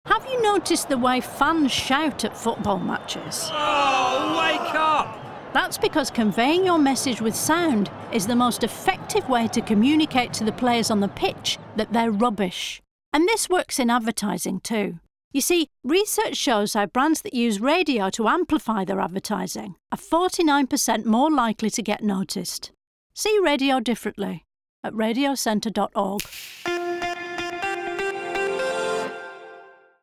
Audio ads, which are voiced by comedian Diane Morgan, were created by Radioville.
From shouting dinner orders abroad to yelling at footballers from the stands, the mix of 30, 20 and 10 second ads comically show how turning up the volume gets you heard – just like great radio advertising.